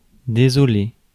Ääntäminen
IPA : /əˈflɪkt/